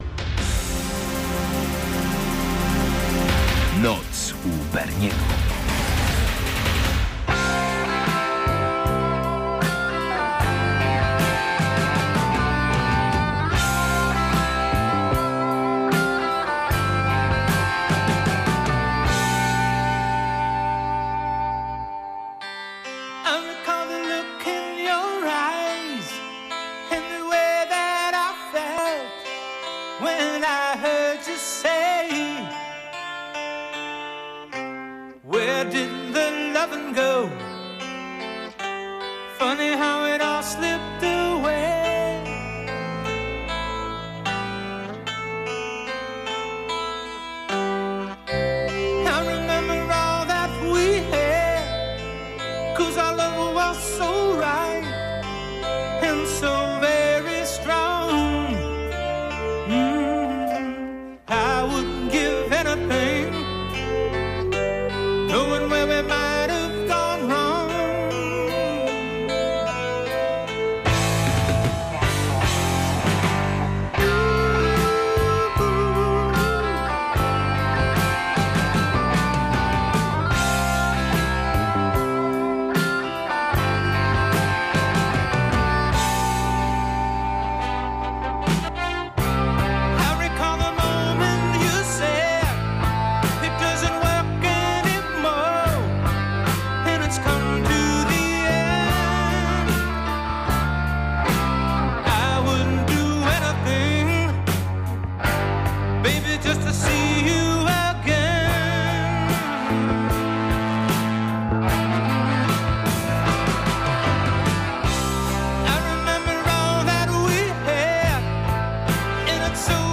Dominujący gatunek: art-rock